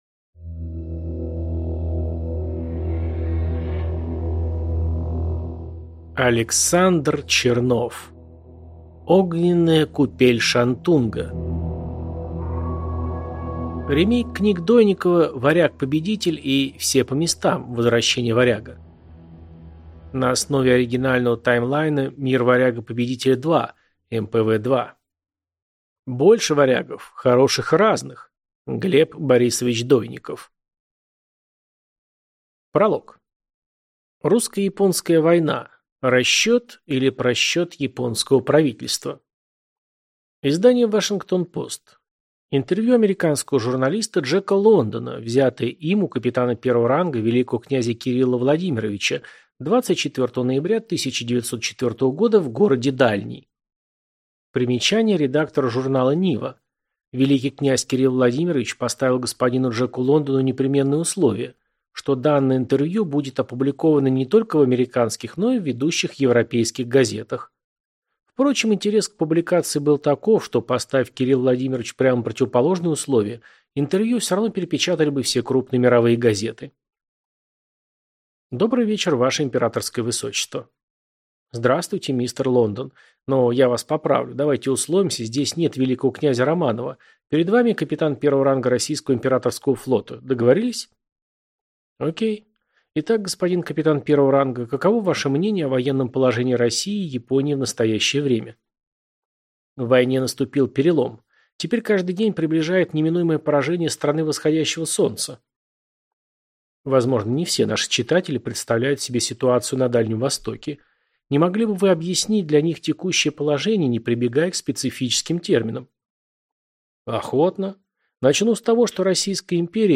Аудиокнига Огненная купель Шантунга | Библиотека аудиокниг